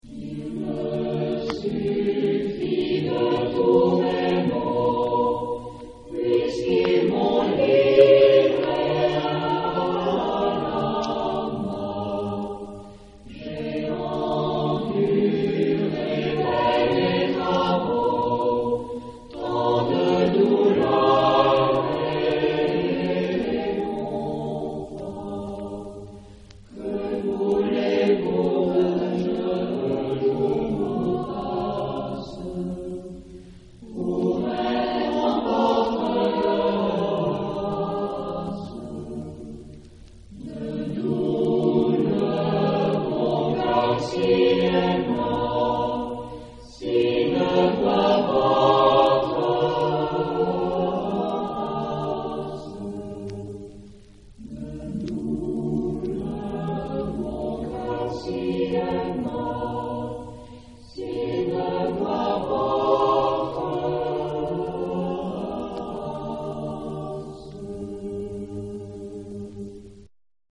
Genre-Style-Forme : Renaissance ; Profane
Type de choeur : SATB  (4 voix mixtes )